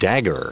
Prononciation du mot dagger en anglais (fichier audio)
Prononciation du mot : dagger